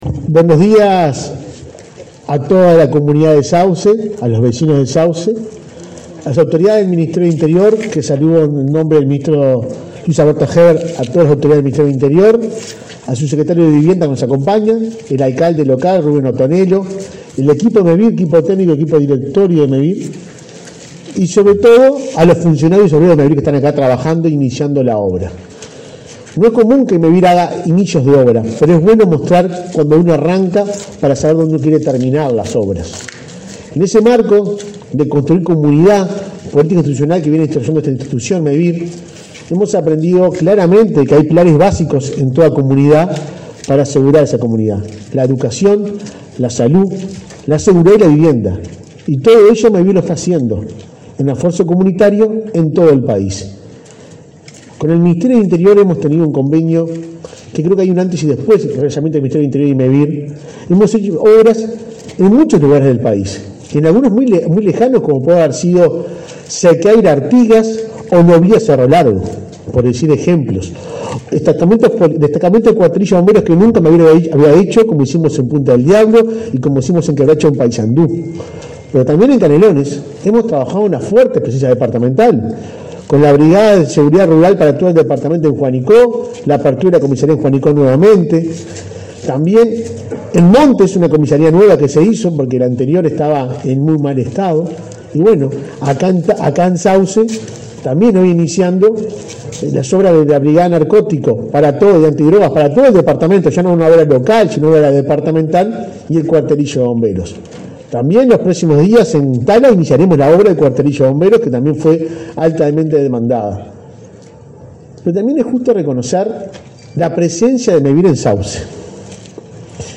Conferencia de prensa por lanzamiento de obras en cuartelillo de bomberos en Sauce
Participaron en la actividad el ministro del Interior, Luis Alberto Heber, y el presidente de Mevir, Juan Pablo Delgado.